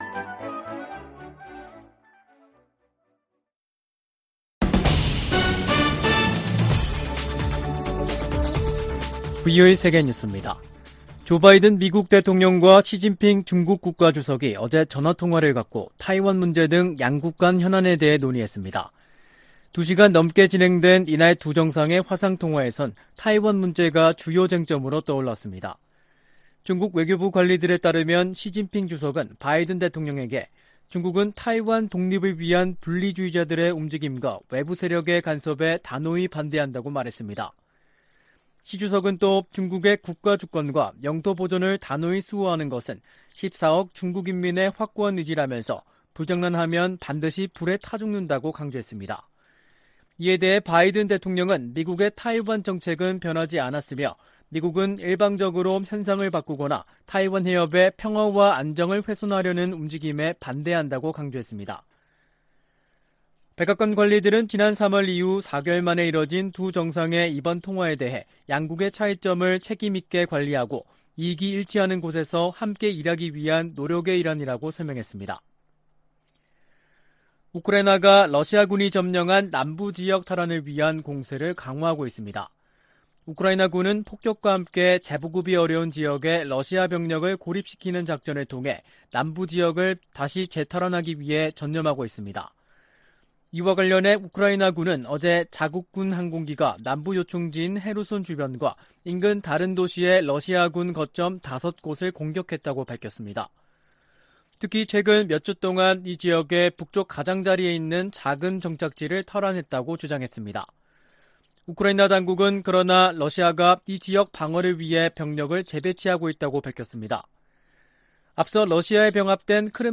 VOA 한국어 간판 뉴스 프로그램 '뉴스 투데이', 2022년 7월 29일 3부 방송입니다. 미 국무부는 김정은 국무위원장의 전승절 기념행사 연설에 직접 반응은 내지 않겠다면서도 북한을 거듭 국제평화와 안보에 위협으로 규정했습니다. 핵확산금지조약(NPT) 평가회의에서 북한 핵 문제가 두 번째 주부터 다뤄질 것이라고 유엔 군축실이 밝혔습니다.